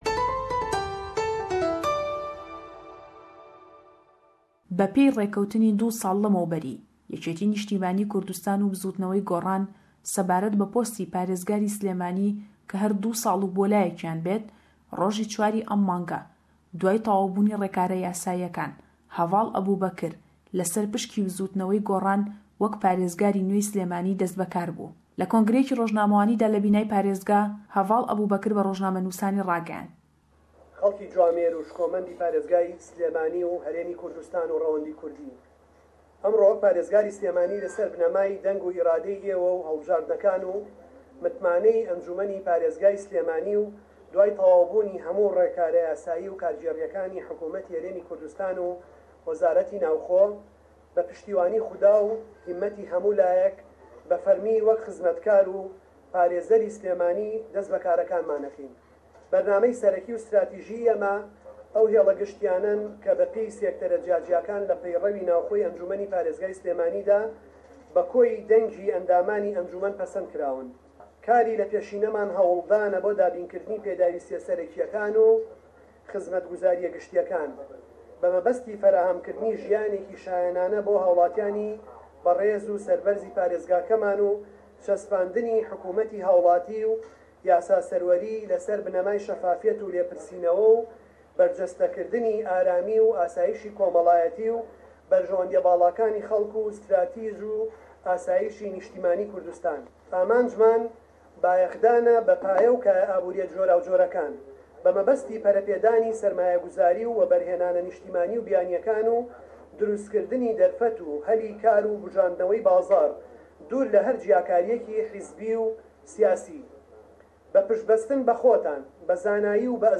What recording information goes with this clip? reporting from Slemani